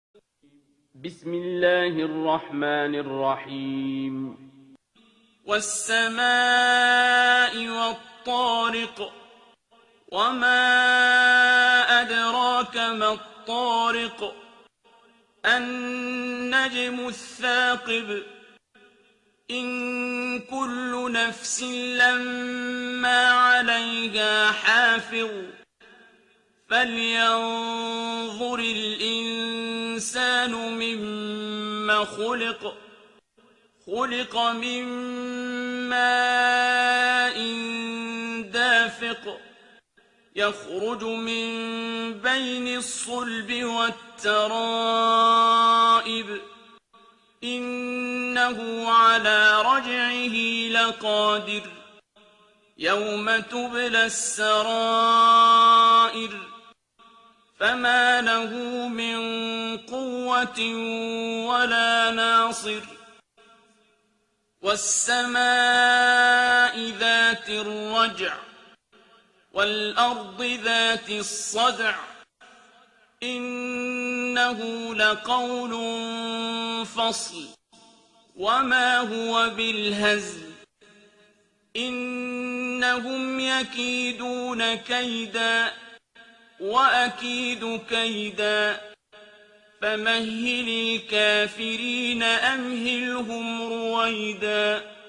ترتیل سوره طارق با صدای عبدالباسط عبدالصمد
086-Abdul-Basit-Surah-At-Tariq.mp3